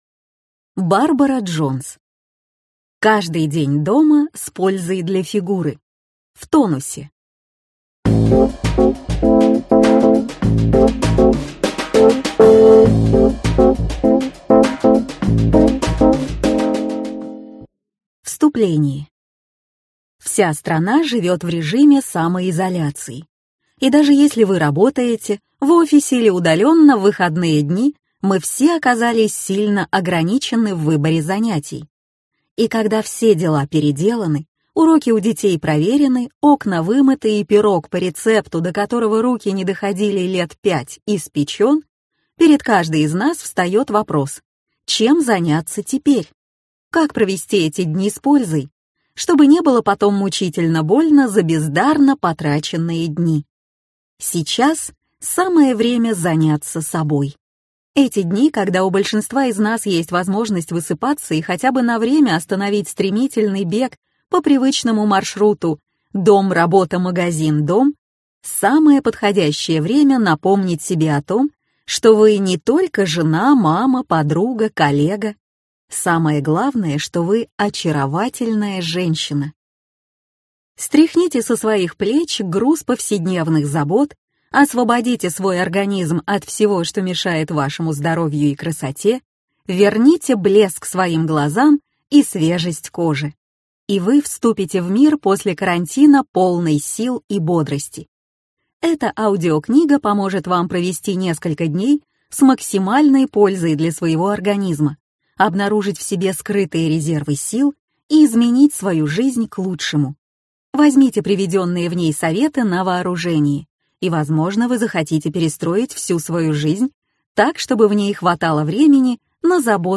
Аудиокнига Каждый день дома – с пользой для фигуры! В тонусе!